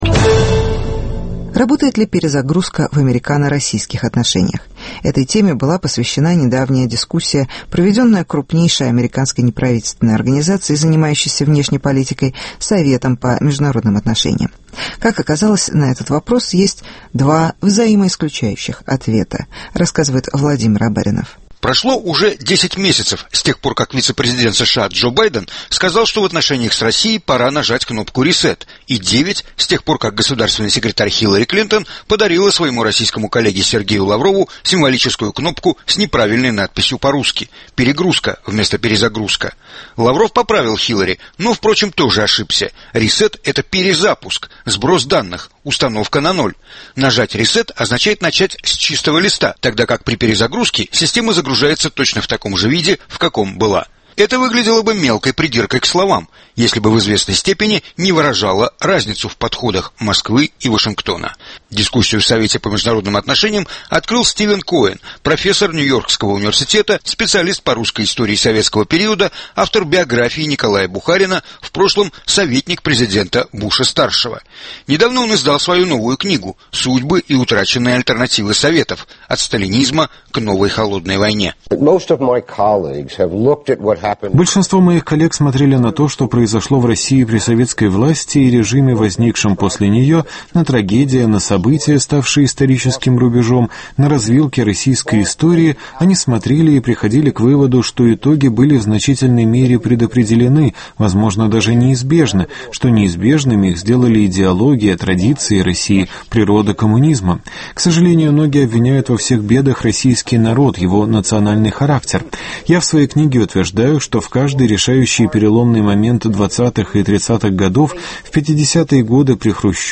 Работает ли "перезагрузка", дискуссия в Совете по внешней политике США.